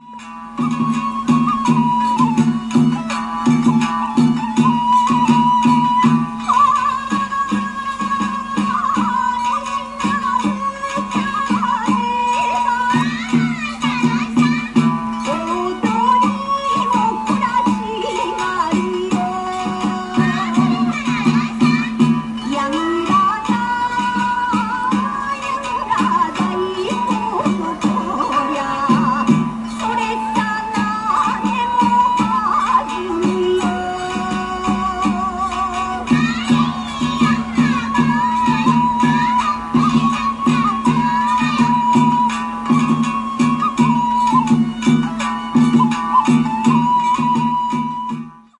描述：盂兰盆舞（日本传统舞蹈） Aug.2007/Sapporo,JAPAN